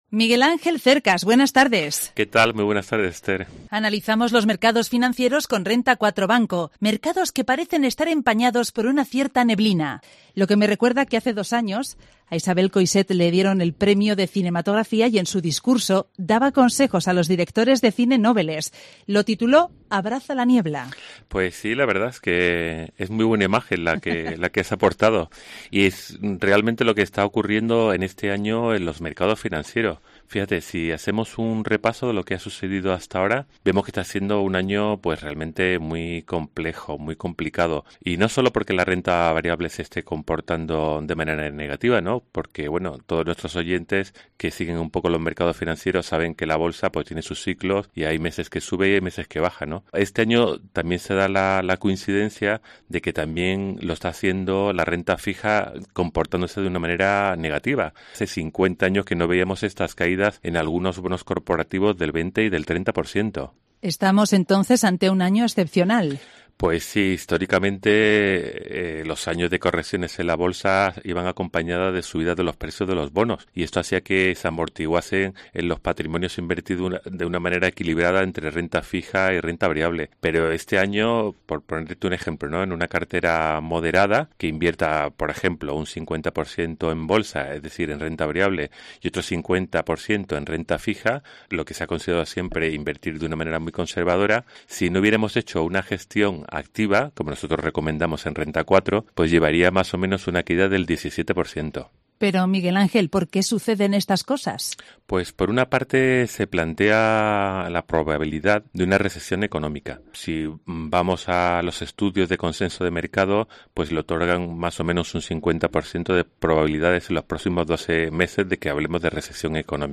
participa hoy en el programa local de Cope León para analizar los mercados financieros.